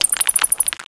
pokeemerald / sound / direct_sound_samples / cries / dwebble.aif